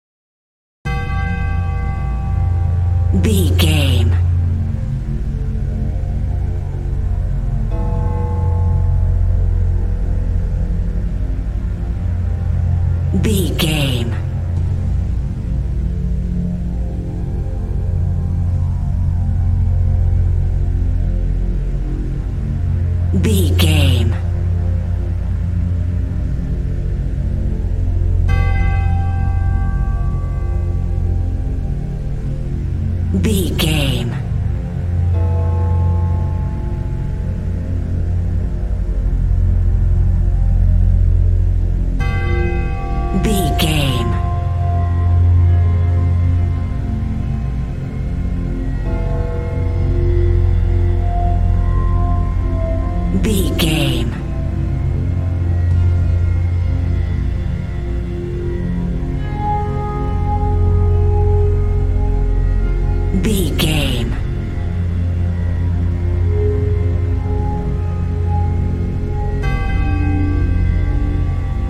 A scary piece of Horror music written for kids and children.
Ionian/Major
tension
piano
synthesiser